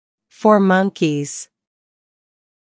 4-monkeys.mp3